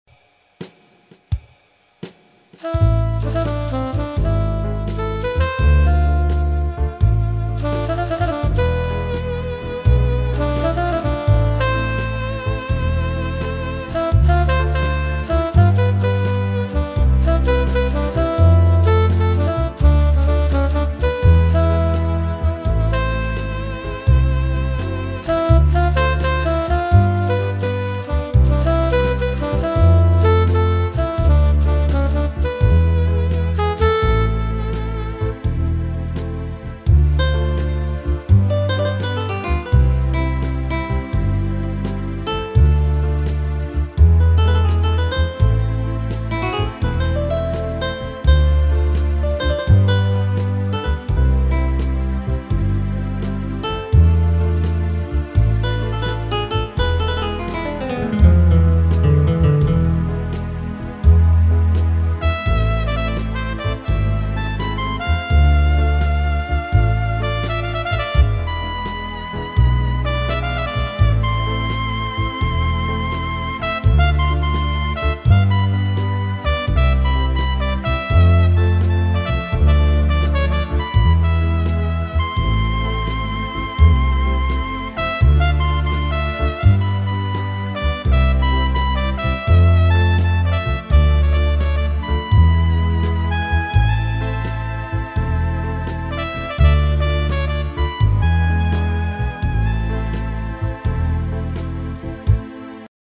Всем доброго дня! настроение в музыке без слов... на рассвете...